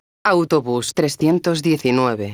megafonias exteriores